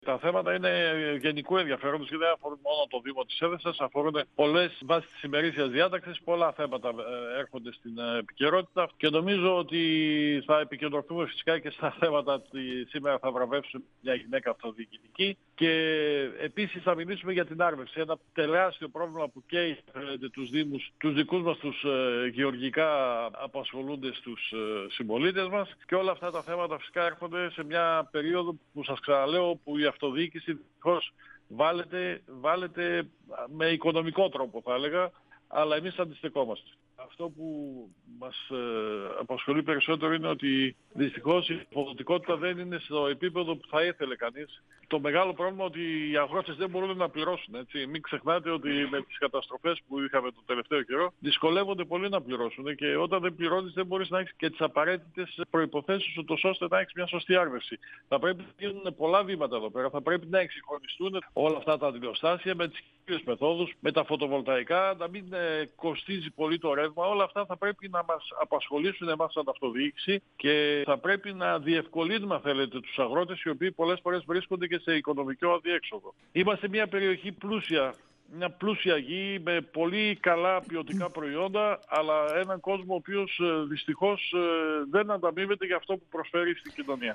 Ο δήμαρχος Εδεσσας Δημήτρης Γιάννου, στον 102FM του Ρ.Σ.Μ. της ΕΡΤ3
Συνέντευξη